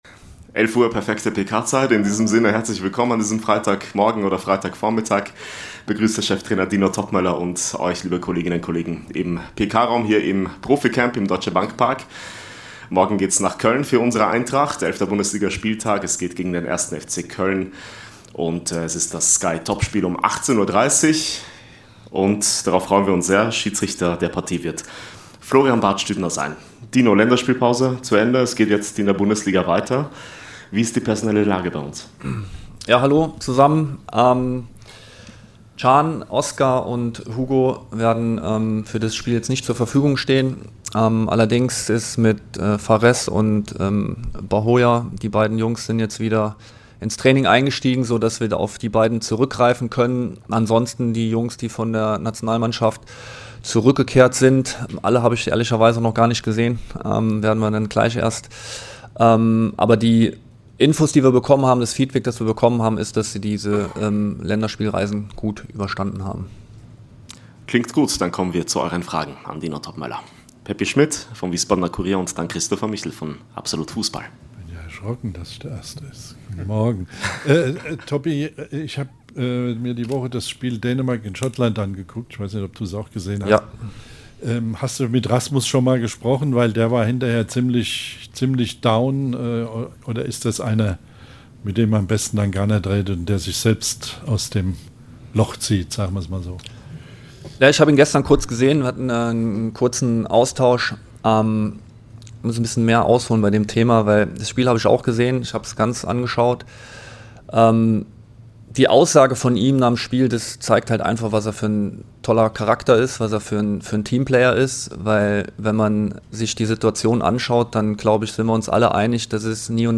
Cheftrainer Dino Toppmöller spricht bei der Pressekonferenz vor Köln über den Gegner und die Personallage.